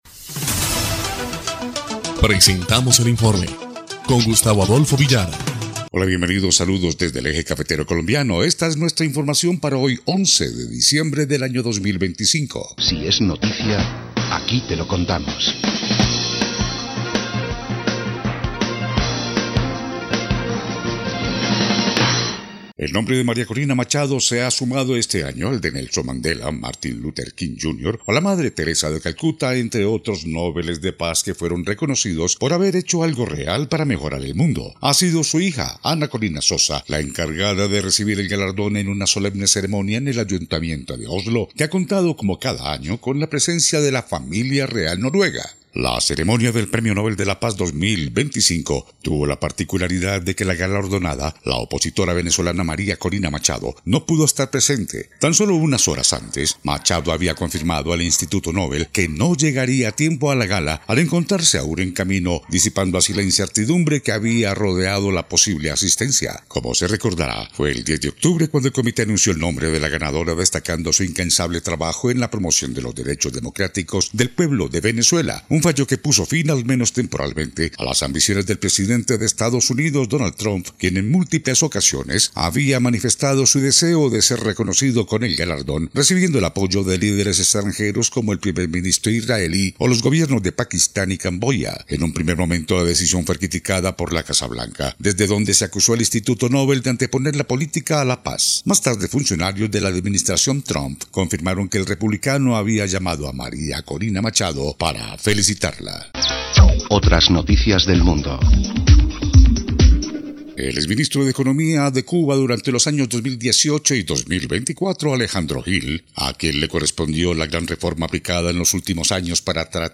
EL INFORME 2° Clip de Noticias del 11 de diciembre de 2025